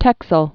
(tĕksəl, tĕsəl)